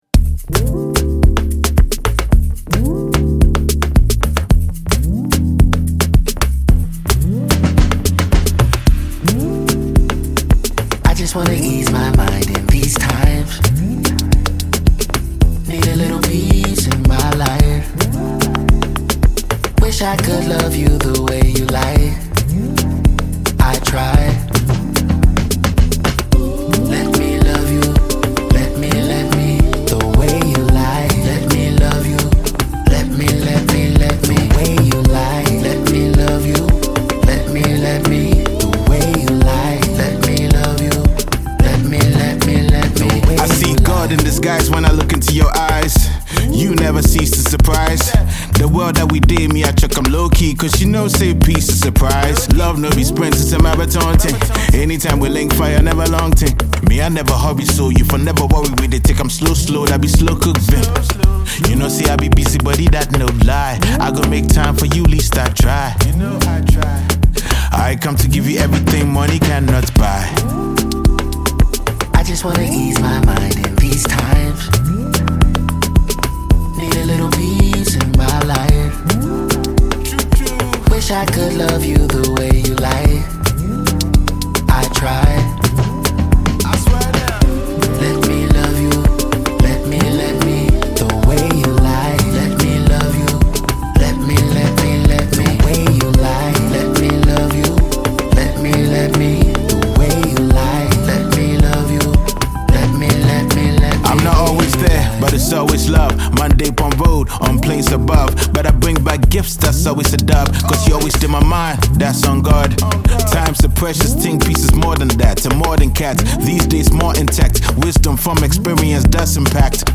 smooth and introspective new track